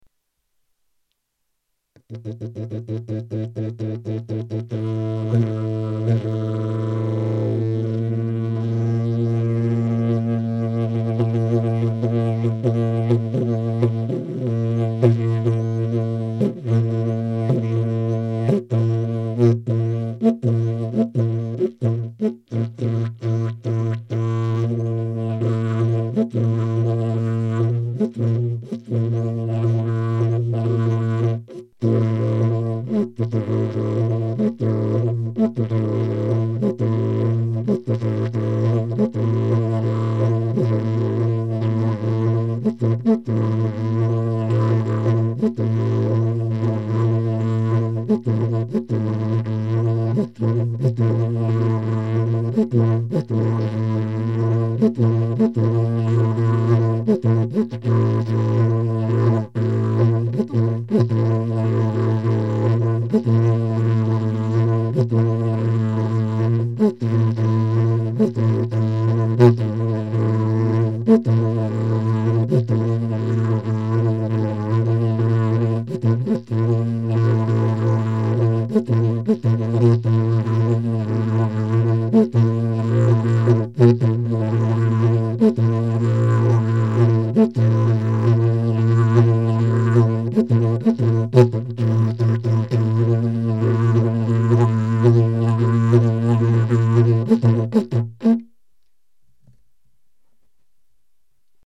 ca commence un peu n'importe comment, à la fin c'est plus des dit te drill drill dit te tit te drill.